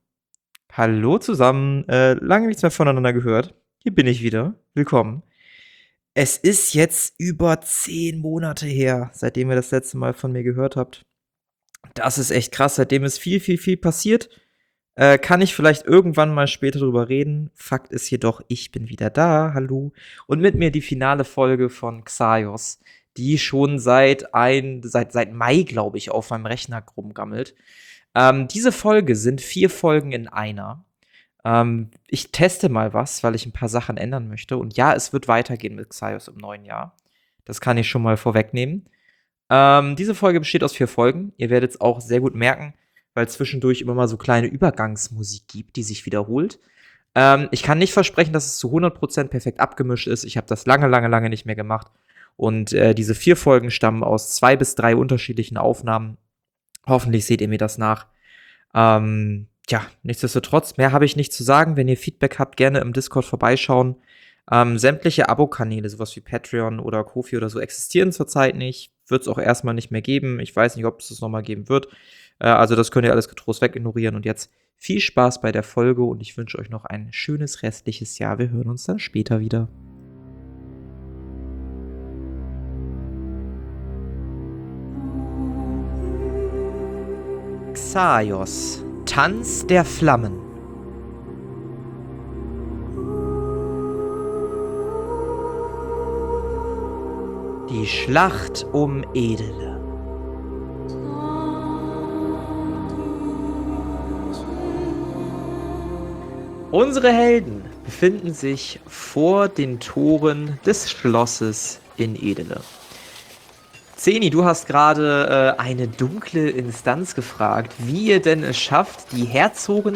Ein Pen and Paper Hörspiel Podcast!